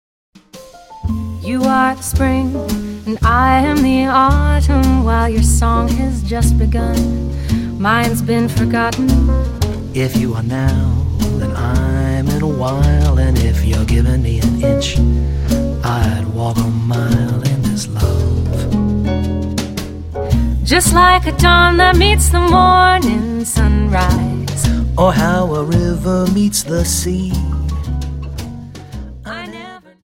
Dance: Slowfox 28